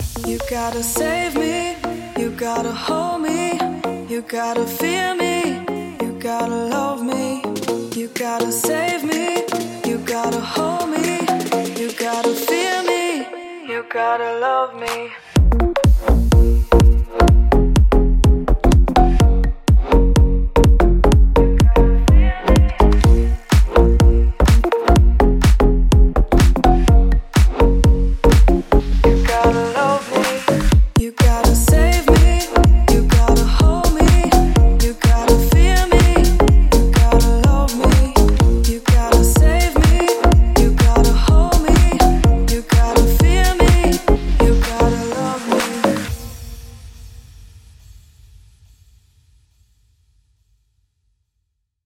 您将获得专业的人声挂钩，人声喊叫声，大声码器，2个工具包等等！
20个声乐挂钩（干+湿）
10个声码器乐曲循环
10个反向声乐